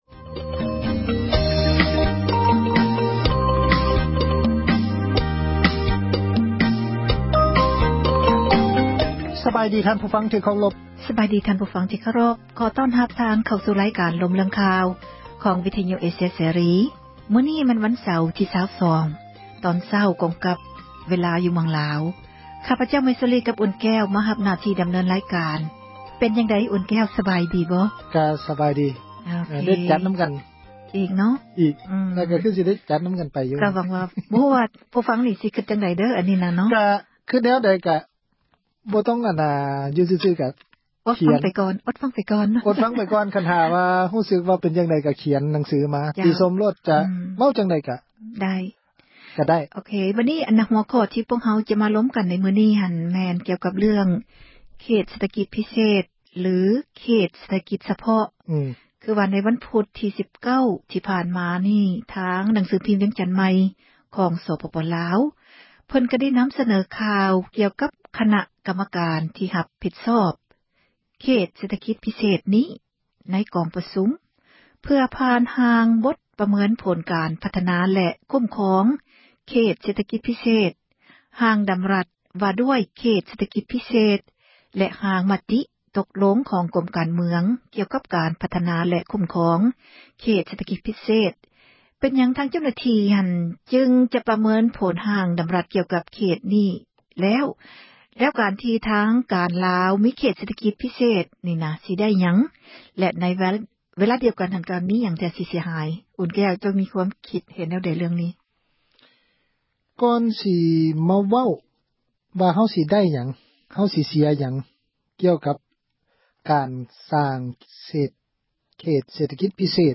ການ ສົນທະນາ